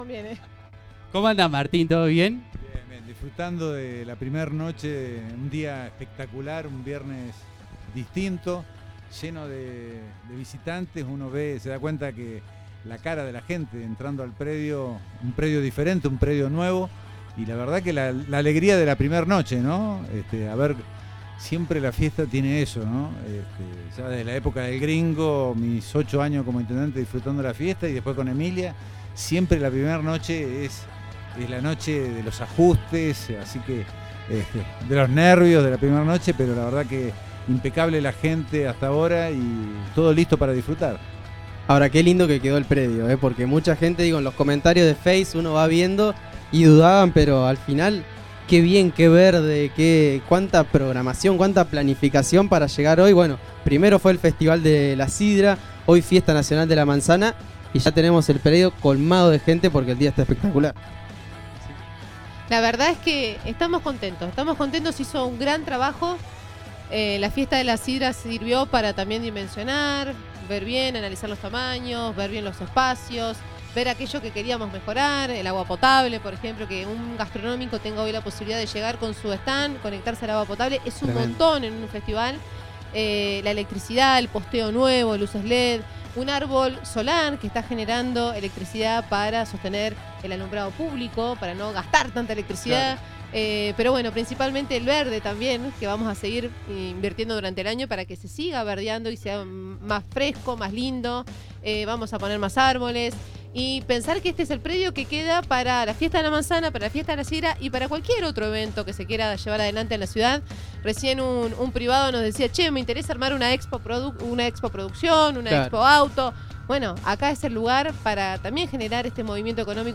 Concluido el acto de apertura y la Bendición de los Frutos, el diputado nacional Martín Soria y la intendente local, María Emilia Soria, respondieron a las consultas de Diario RÍO NEGRO, en el stand donde se realiza la transmisión en vivo para RN Radio.